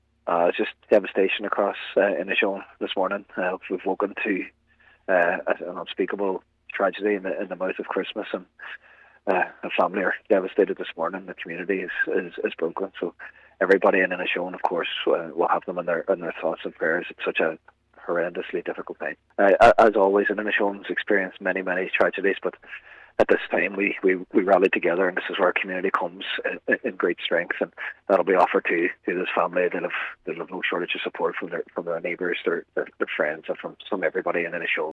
Cathaoirleach of the Inishowen Municipal Disctrict Cllr Jack Murray says the news is devastating: